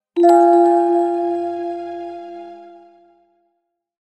SMSの通知音に最適な可愛い感じのオルゴールの音色。